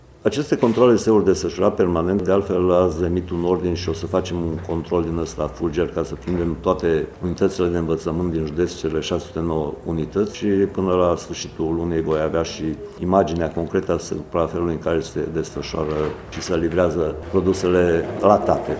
Prefectul de Mureș, Mircea Dușa: